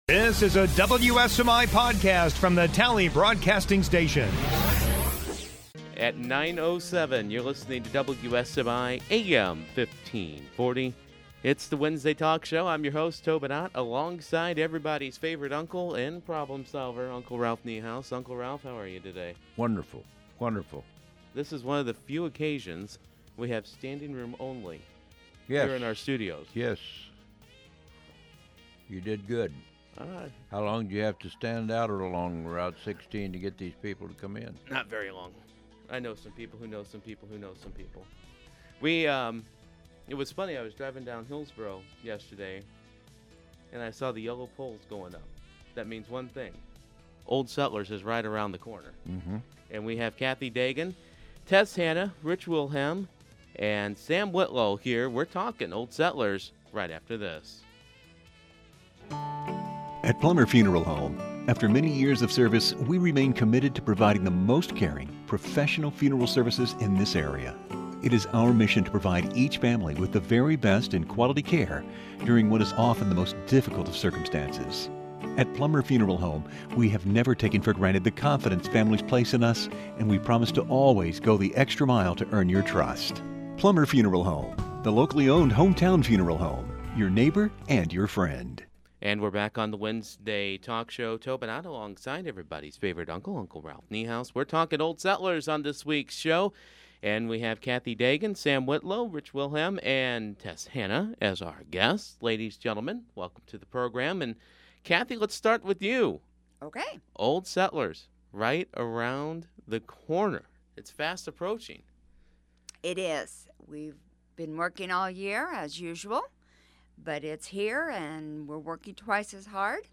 The Wednesday Morning Talk Show